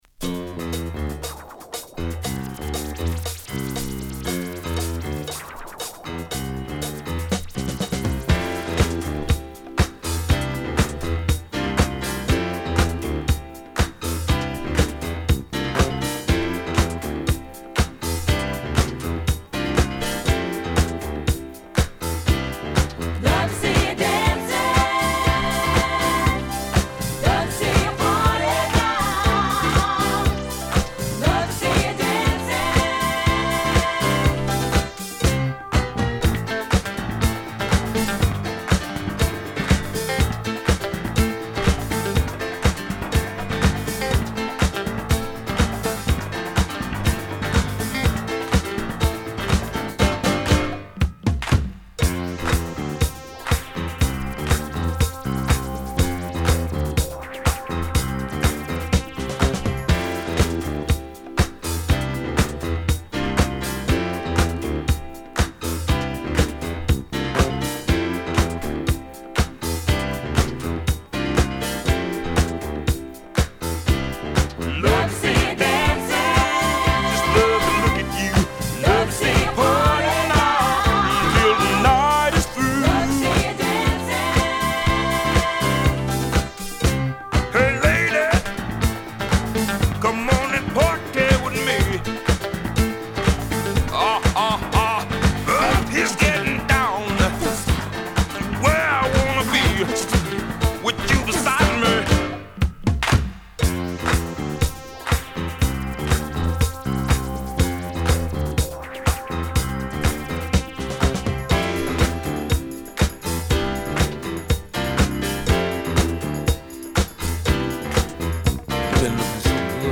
高揚感あるコーラスや隠し味噌の流線形シンセサイザーに踊らされるファンキーディスコ名曲！！